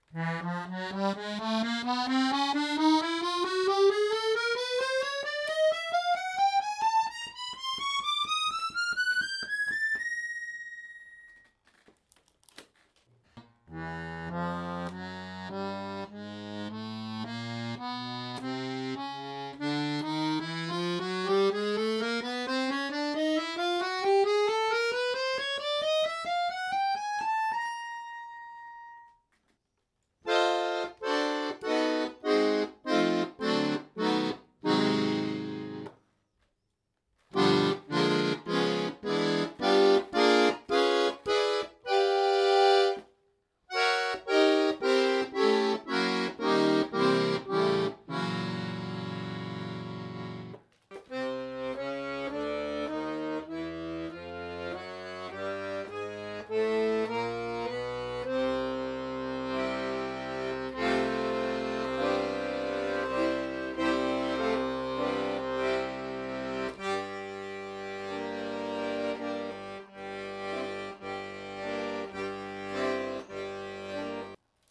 HOHNER (gebraucht) Maestro III B-Griff rot Typ: chromatisch Knopf Preis: 795,- incl.
Frequenz / Tremolofrequenz von a=440/sec: 440/ 16 cent
Register / Klangfarben: 9/ 5 Chöre: 3= LMM